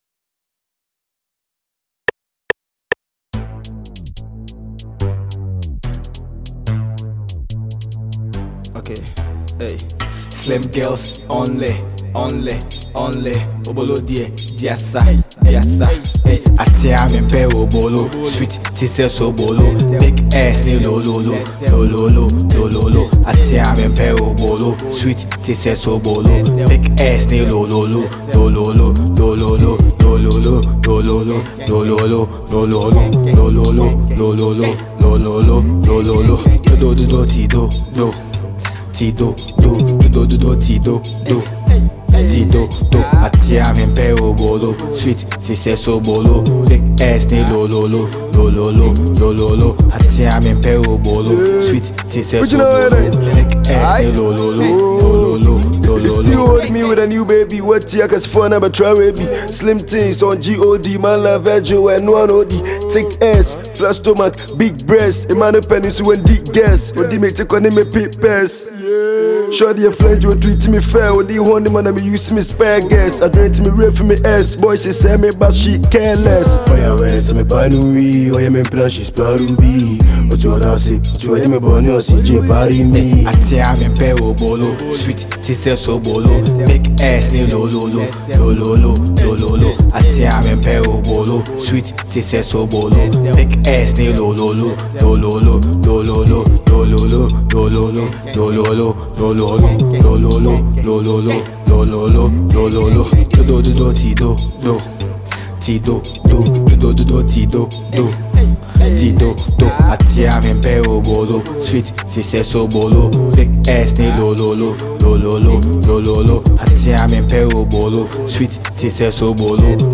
Ghana Music Music